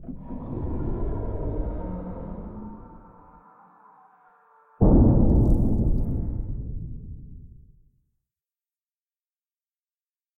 Commotion5.ogg